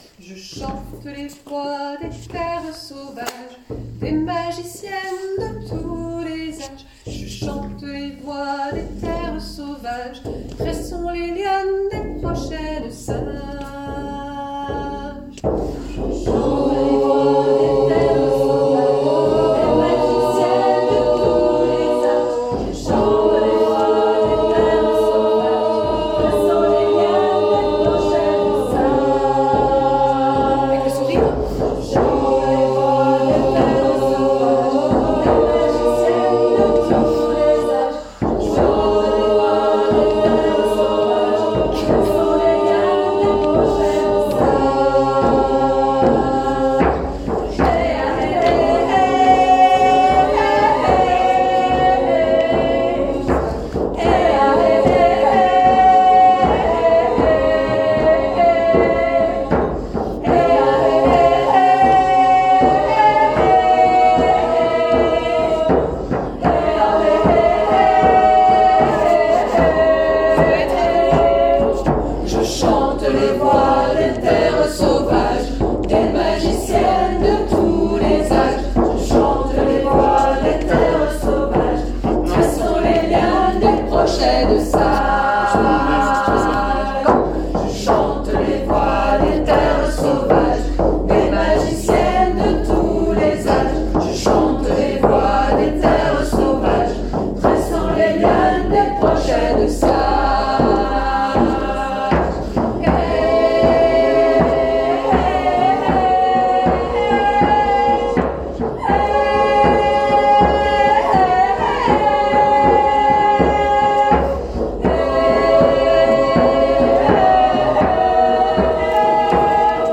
1  x Les femmes à Capela
1  x Tambour et Harmonium
2 X Femmes avec les textes uni
2x Texte pour tous Sprano - Alto - Hommes